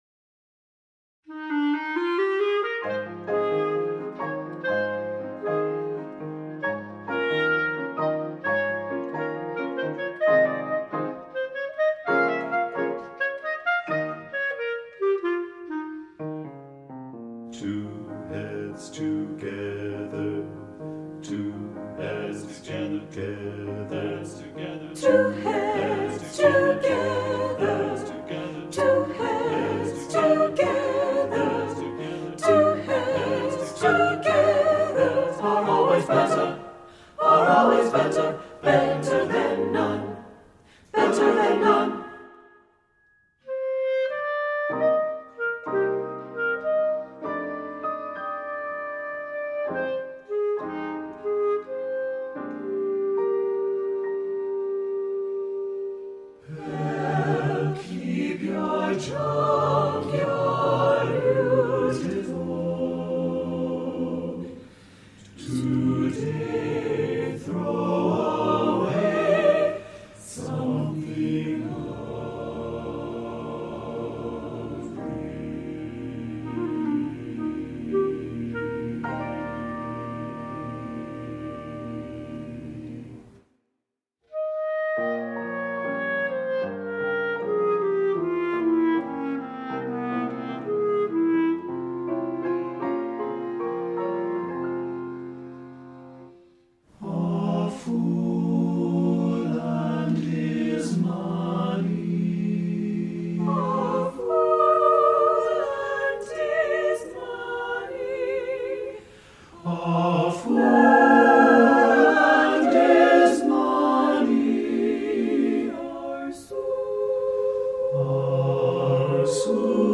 Voicing: SATTBB